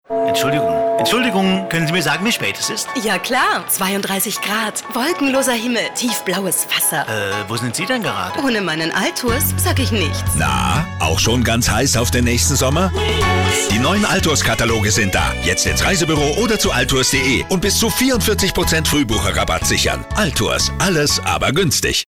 Hörproben der Sprecherin für Österreich & Deutschland, Moderatorin, Schauspielerin, deutsche Synchronsprecherin, deutsche Synchronstimme
stimmprobe werbesprecherin . sprecher werbung . werbespots . tv spots . radiospots
Alltours/TV-spot DE/mp3